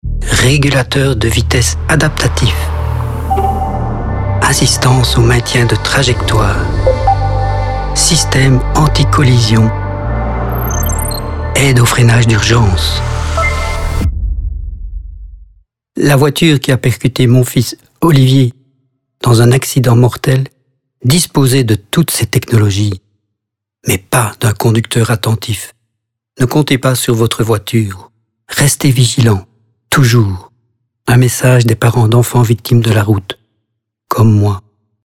Radiospots die klinken als stereotiepe autoreclame.